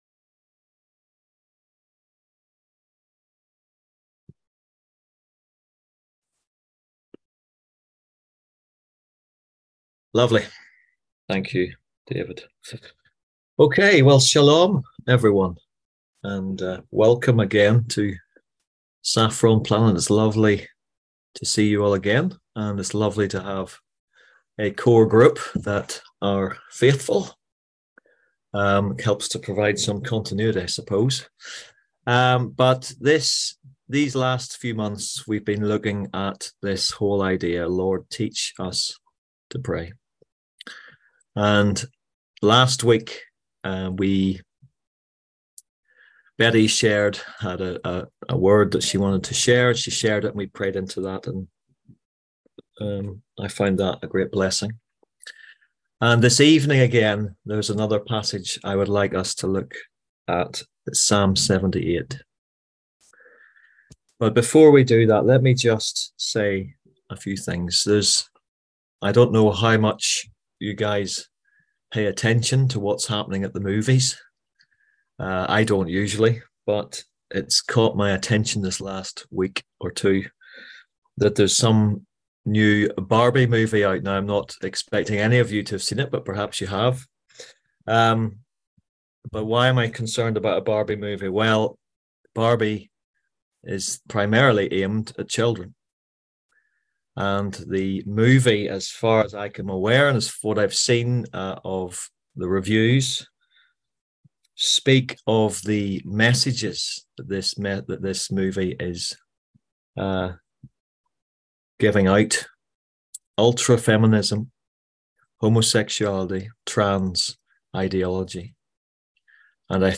On July 31st at 7pm – 8:30pm on ZOOM ASK A QUESTION – Our lively discussion forum.
On July 31st at 7pm – 8:30pm on ZOOM